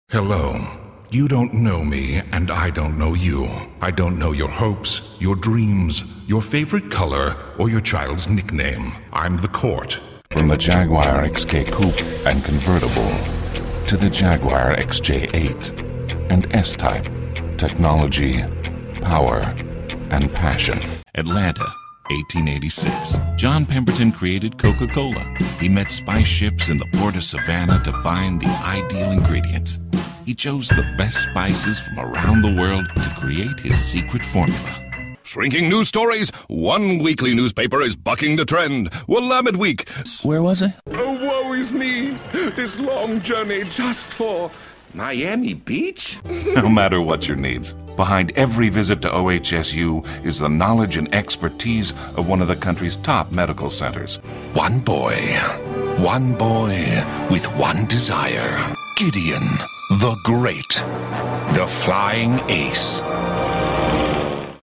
Deep, rich, character, regular guy, actor.
englisch (us)
Sprechprobe: Werbung (Muttersprache):
A memorable voice, deep and smooth or rich and rough. Characters a speciality and of course, good old Dad is in the mix.